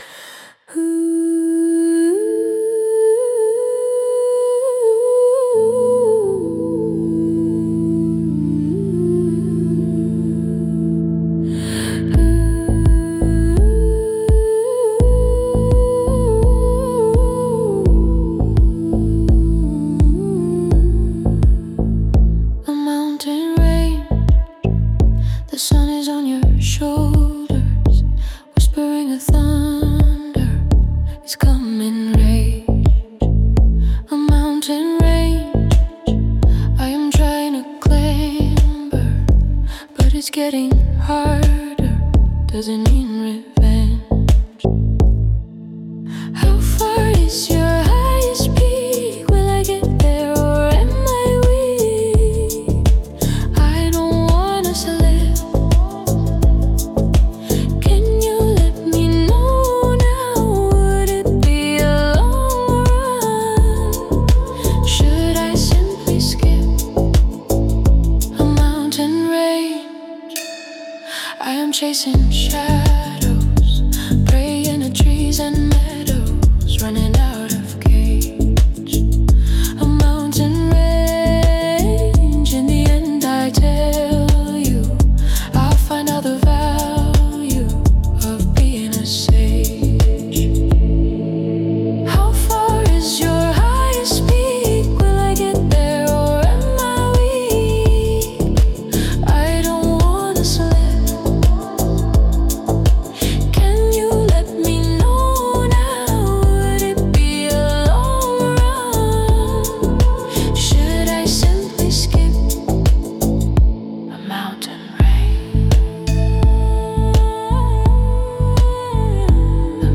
Пример 4: Дрилл и качовый грув
дрилл, средний темп, скользящий качовый грув, глубокий кик, плотный снейр, редкие хэты, напряжённая ритм-секция, мрачная подача